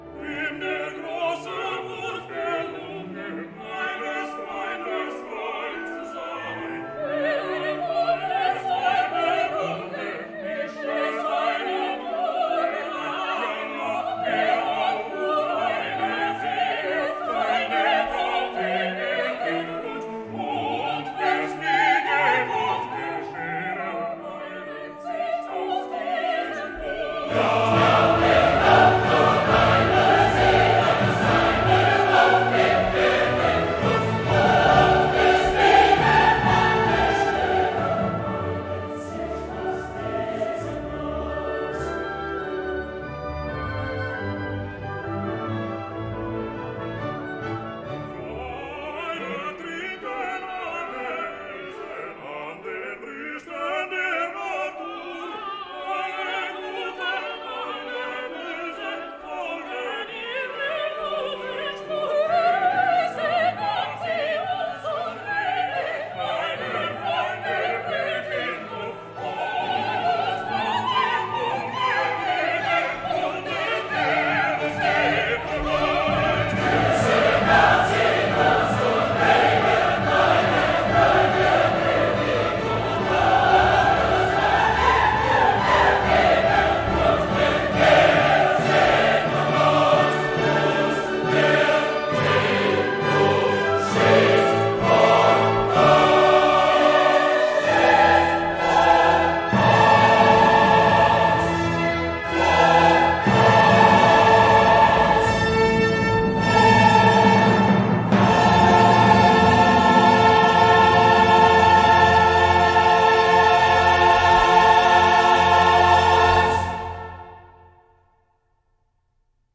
Interestingly, Beethoven uses a musical device where the text is first sung by just a few, in this case a quartet of singers, who then vibrationally provide the impetus for a larger collective, the chorus, to offer their agreement. And oh, what pure glory that is when the chorus comes in and offers their agreement in full-throated response!
By the way, the snippets of audio we’ve been listening to are from that 1972 recording of the Chicago Symphony Orchestra and Chorus, Georg Solti conducting, recorded at the Krannert Center of Performing Arts on the campus of the University of Illinois.